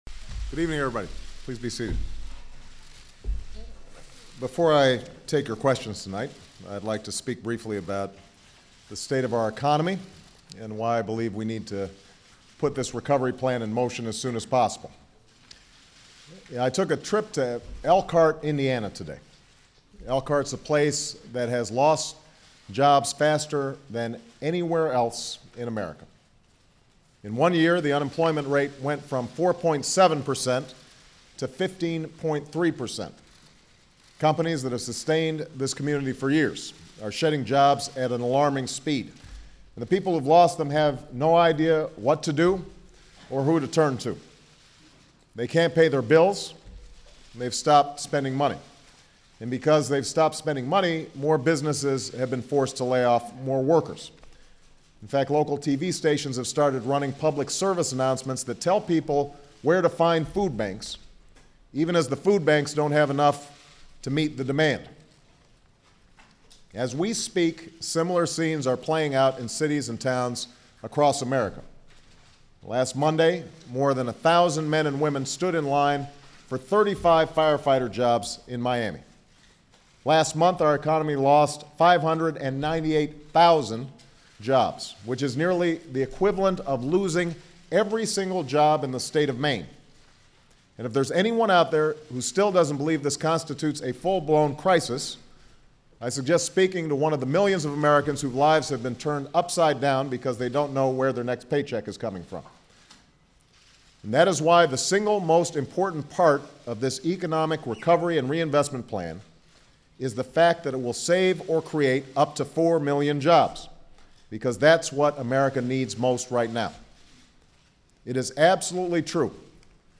American Rhetoric: Barack Obama - First Presidential Prime Time Press Conference